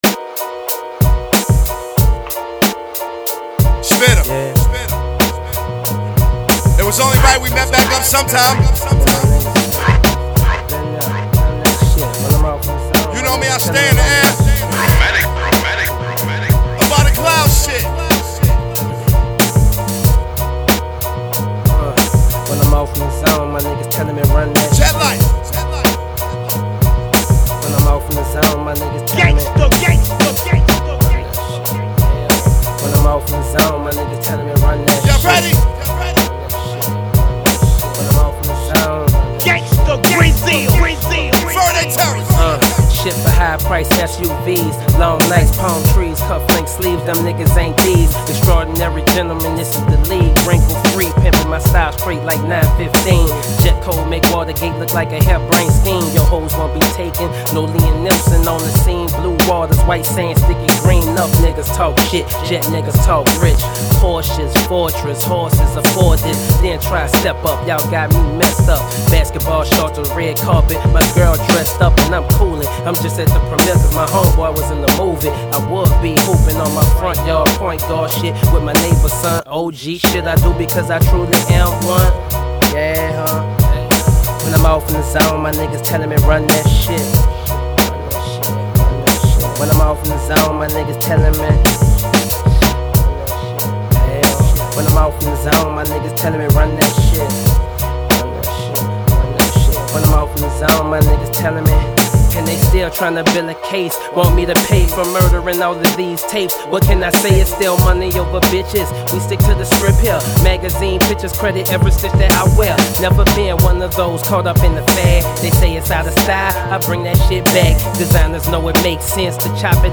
This joint is made for a late night cruise on the streets.